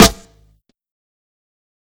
Metro Snare [Hip-Hop].wav